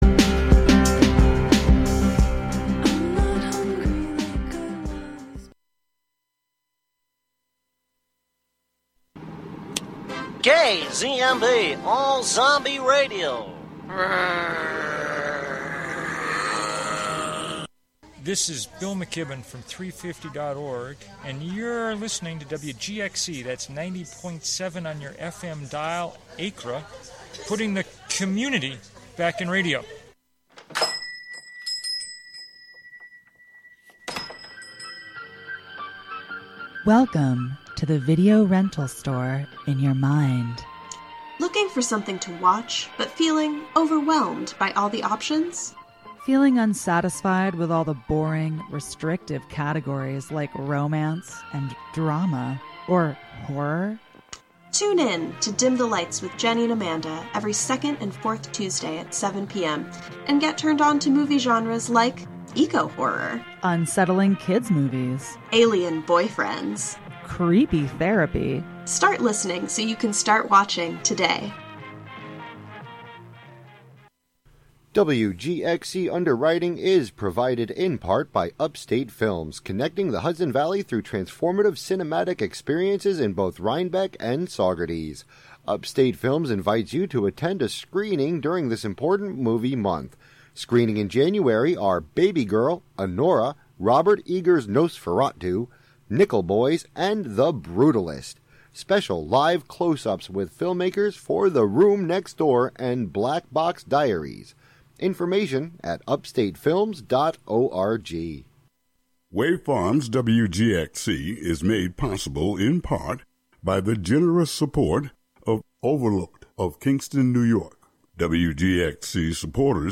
Two compelling voices from opposite ends of the political spectrum come together for a candid conversation on the theme “Race & Republicans” with a focus on the specialized topic, “Reinventing Racism.”